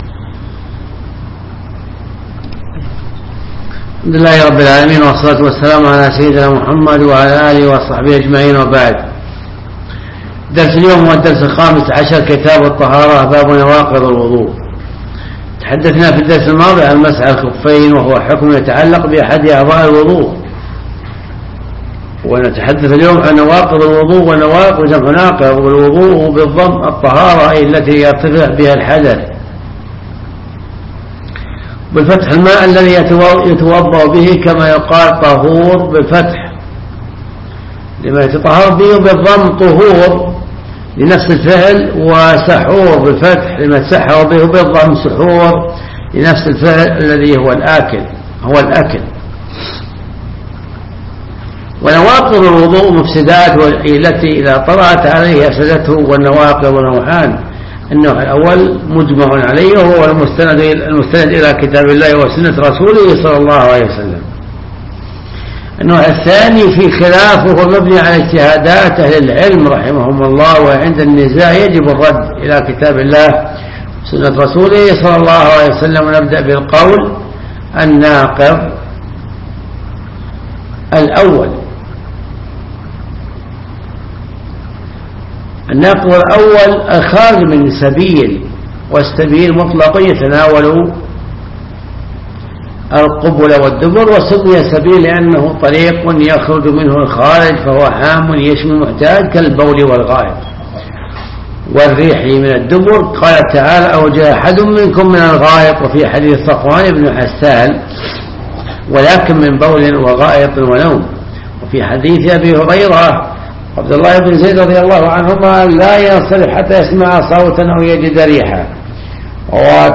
الدرس الخامس عشر: باب نواقض الوضوء